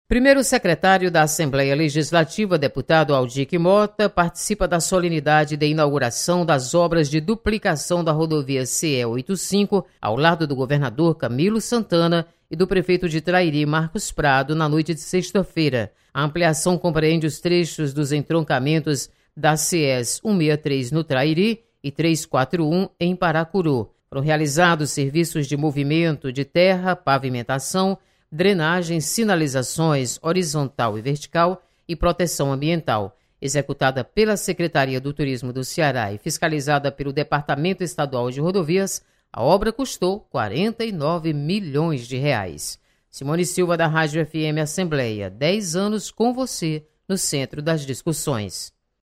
Audic Mota participa de inauguração de rodovia. Repórter